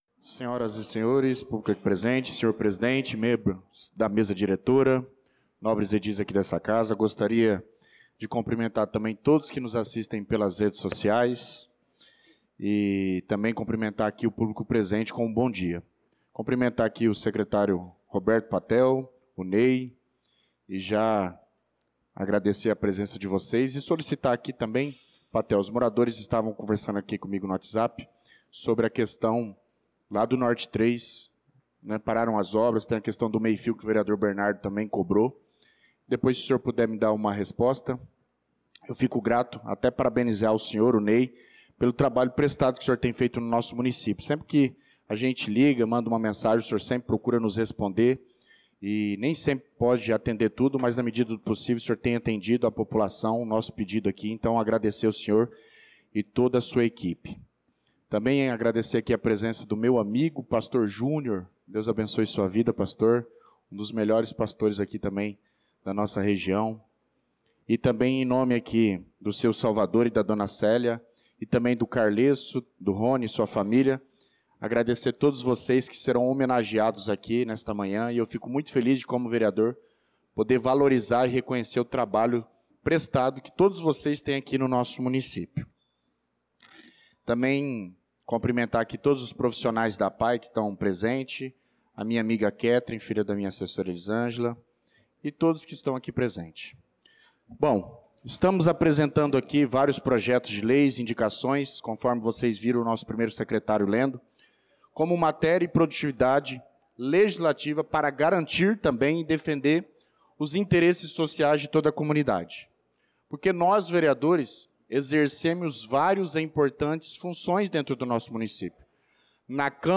Pronunciamento do vereador Douglas Teixeira na Sessão Ordinária do dia 16/06/2025.